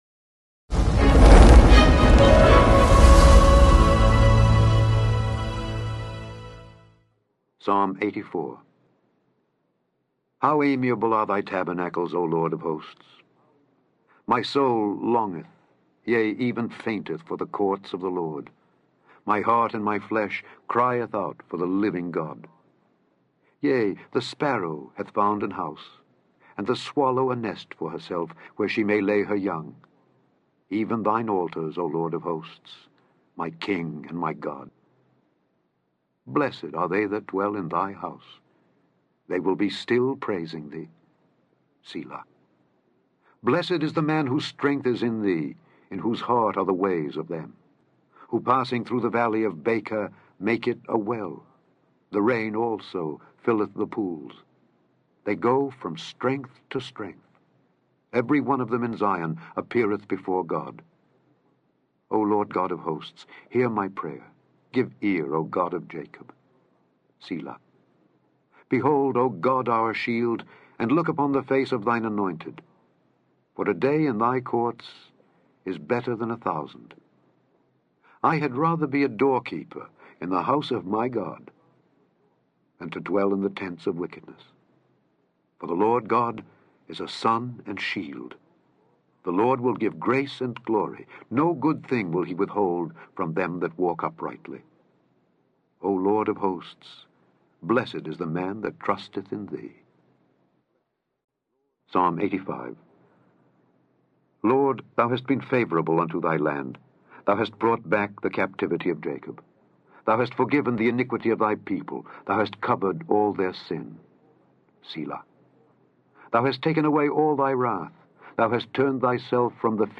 Daily Bible Reading: Psalms 84-86
In this podcast, you can hear Alexander Scorby read Psalms 84-86 to you.